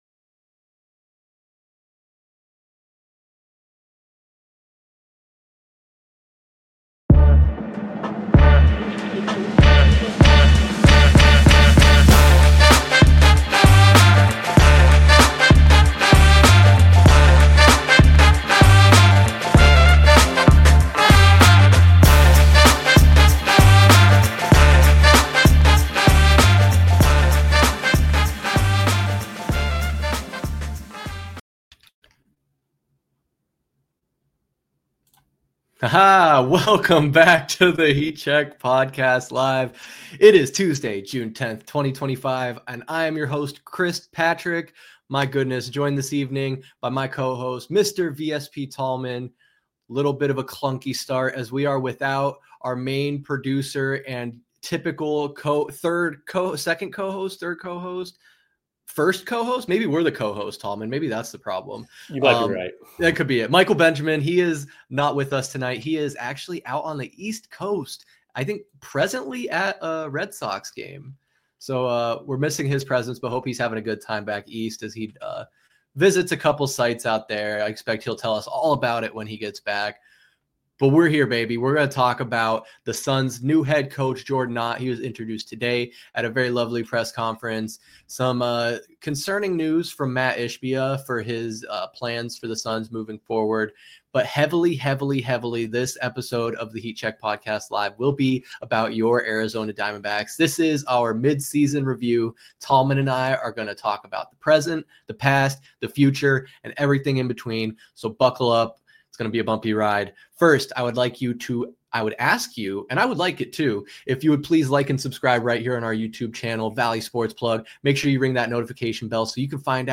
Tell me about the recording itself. discuss Arizona Sports and Pop Culture - Live from Studio B